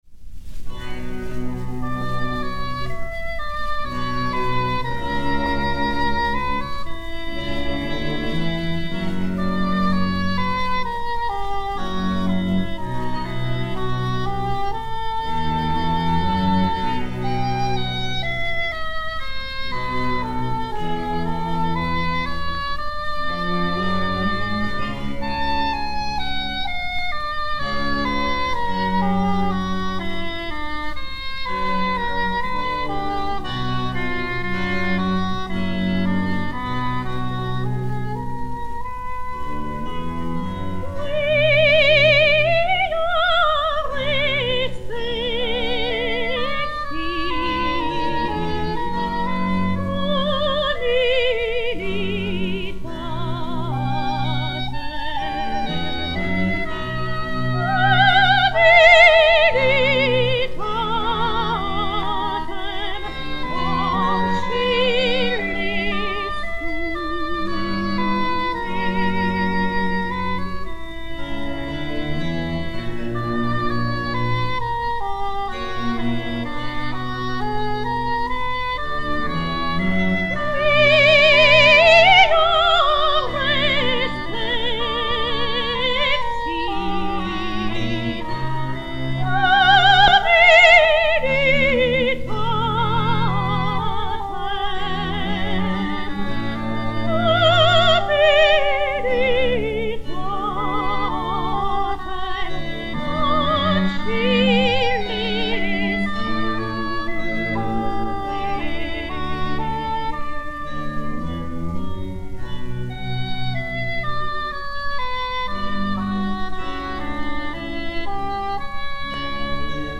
hautbois d'amour
enr. au Théâtre des Champs-Elysées le 23 février 1948